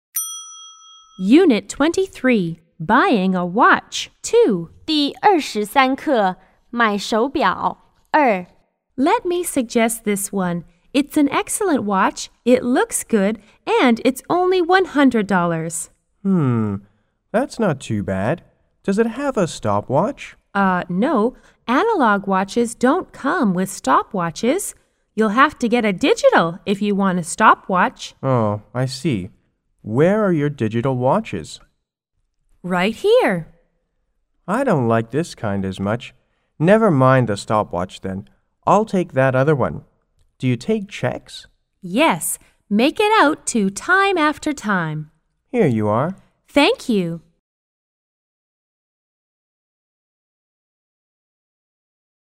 S= Salesperson C= Customer